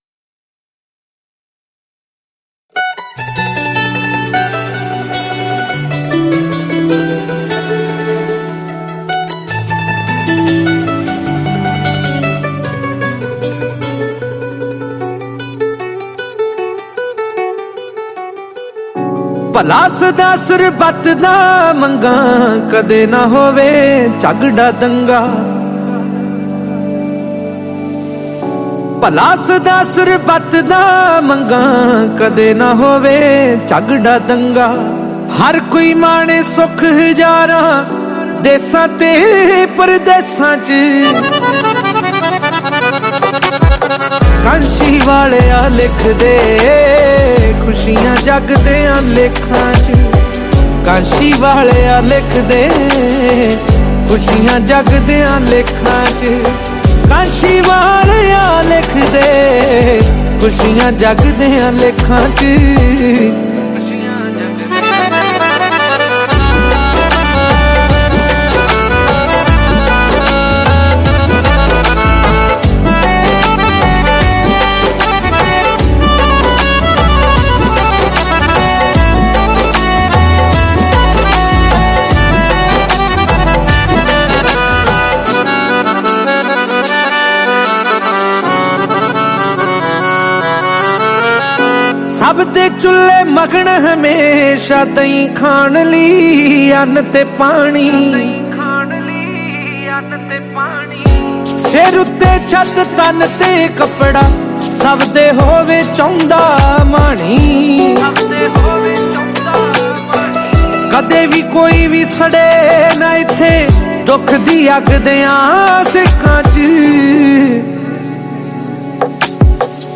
Category: Shabad Gurbani